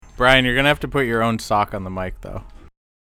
Sock on Mic
sock-on-mic.mp3